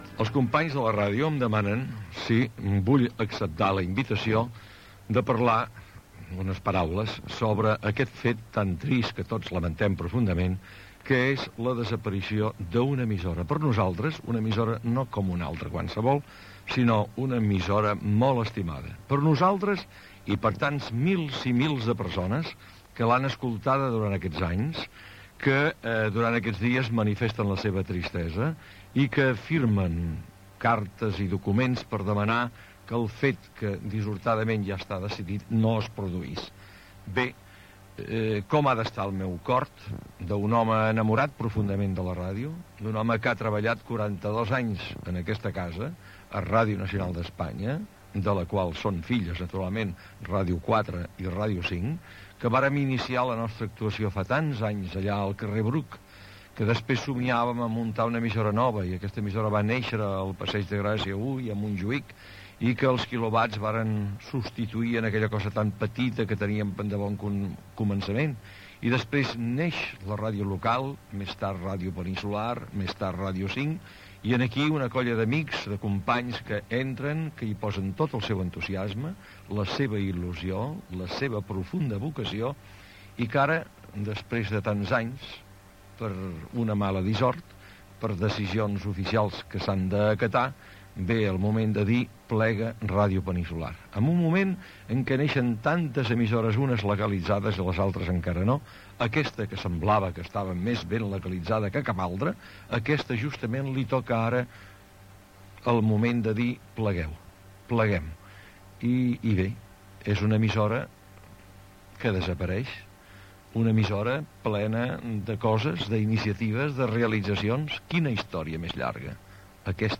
Tancament de l'emissora.